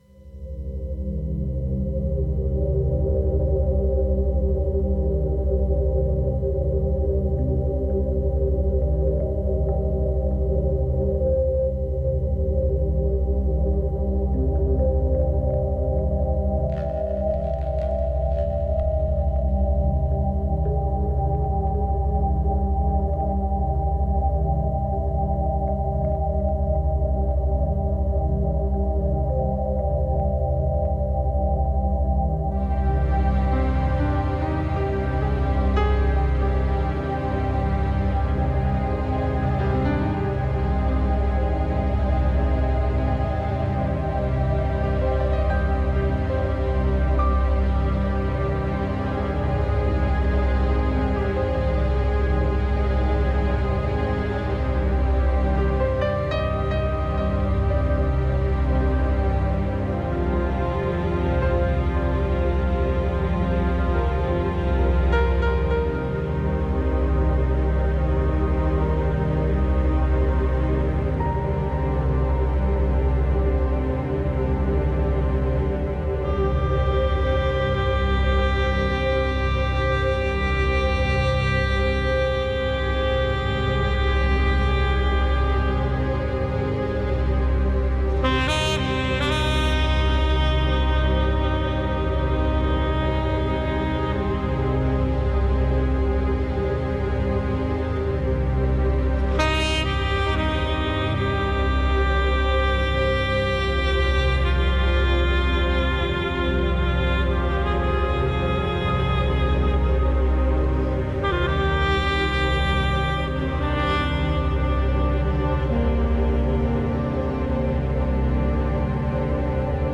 SPIRITUAL JAZZ / DARK AMBIENT / DOWNTEMPO